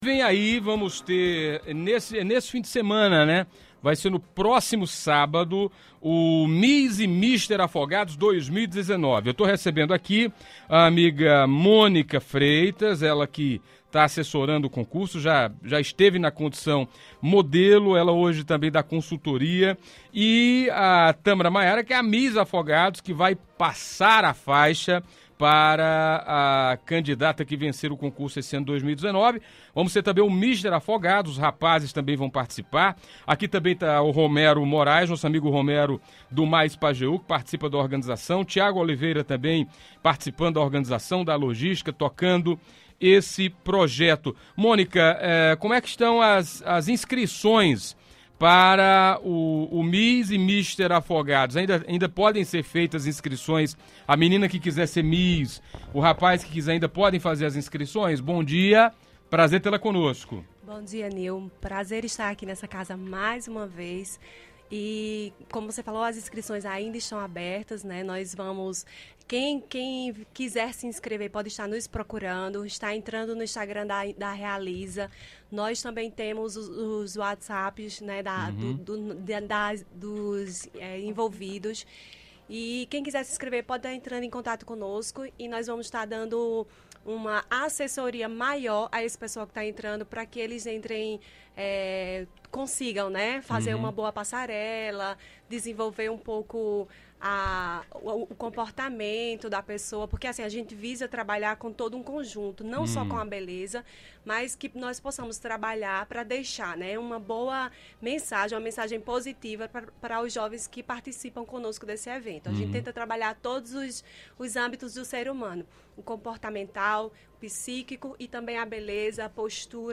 Eles falaram sobre a organização e expectativa do evento, que ainda está com as inscrições abertas. Ouça a entrevista na íntegra: